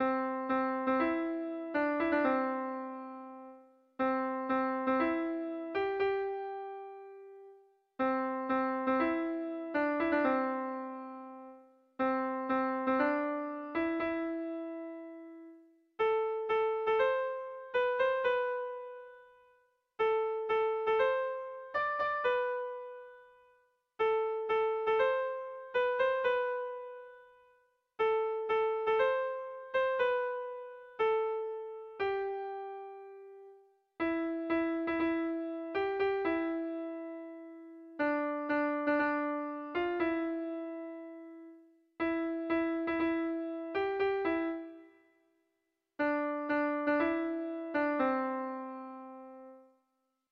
Sentimenduzkoa
Hamabiko txikia (hg) / Sei puntuko txikia (ip)
A1A2B1B2DD